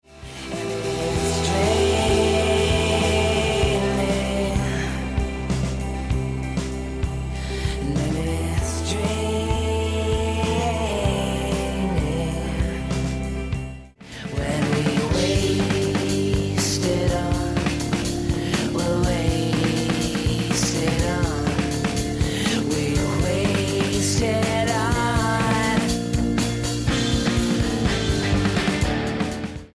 I love that cello